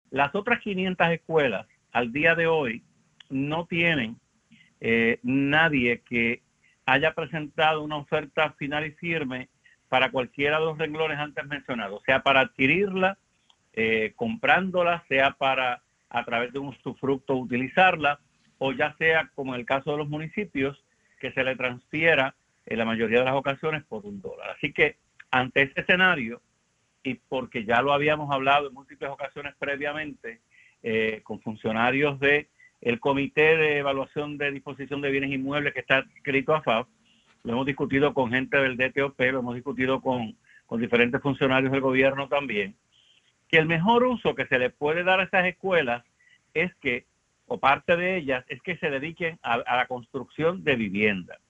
Porque no todas las escuelas tienen alcantarillados, porque no todas las escuelas tienen la mejor infraestructura, pero yo estoy seguro, por los análisis y los estudios que hicimos en el pasado de esas 401, que sí debe haber 250 escuelas que tengan alcantarillado sanitario, que tengan el servicio de agua potable al frente, que tengan el servicio de energía eléctrica, etcétera“, indicó el procurador en entrevista con Radio Isla.